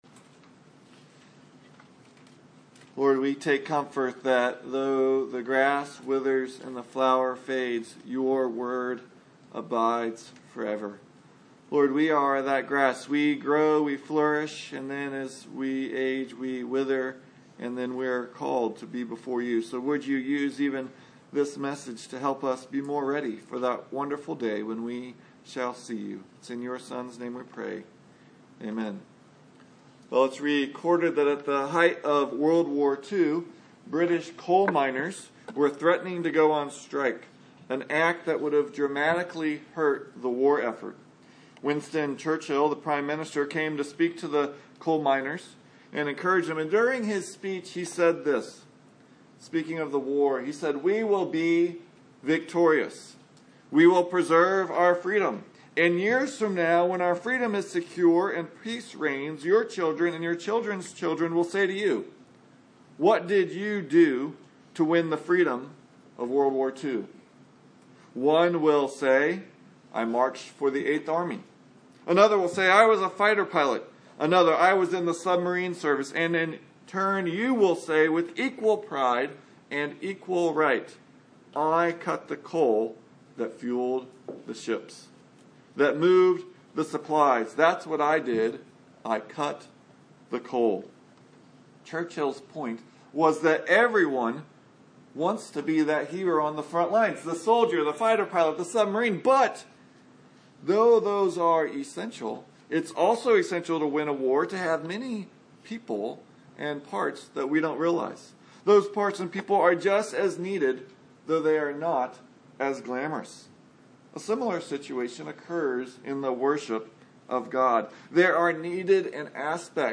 Numbers 7 & 8 Service Type: Sunday Morning Numbers 7 and 8 provide the essentials needed for Israel to worship God.